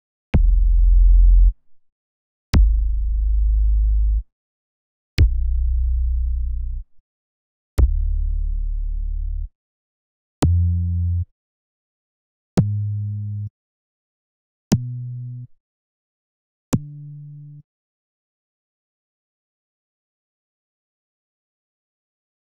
29_MoogLoDeepBass_F+3_1-2.wav